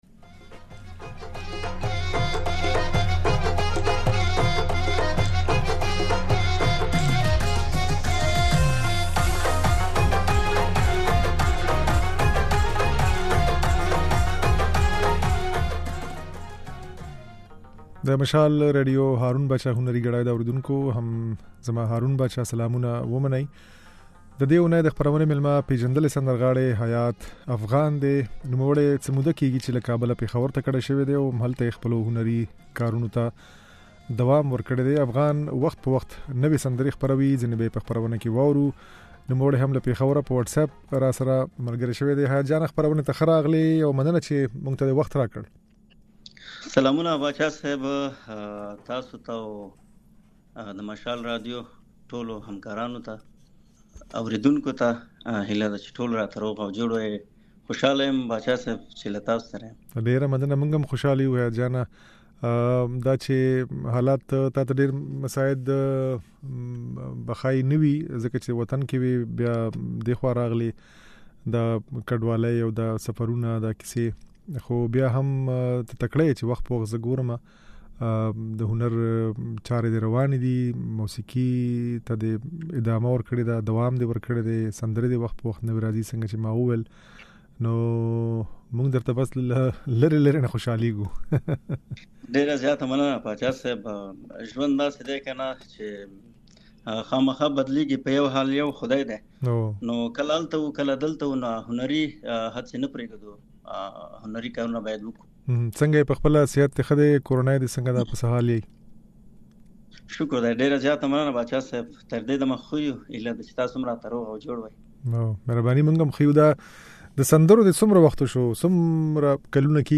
خپرونې مېلمه پېژندلی سندرغاړی
ځينې سندرې يې په خپرونه کې اورېدای شئ.